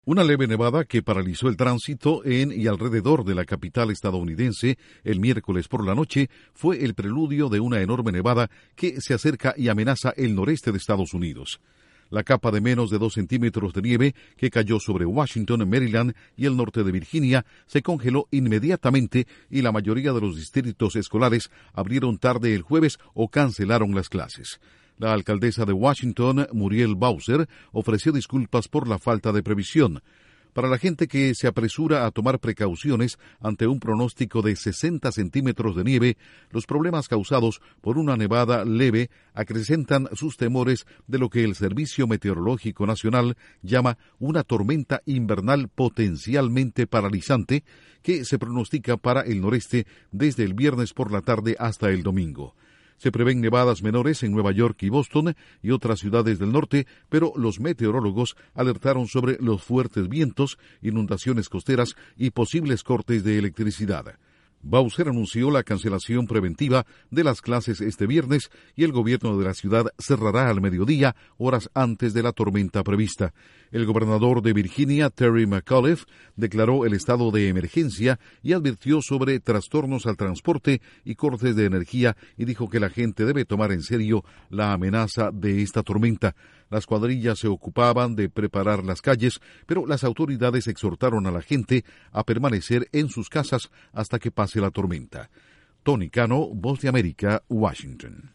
Ciudades del noreste de Estados Unidos, entre ellas Washington y Nueva York, se preparan para la llegada de lo que los meteorólogos han descrito como una gigantesca nevada. Informa desde la Voz de América en Washington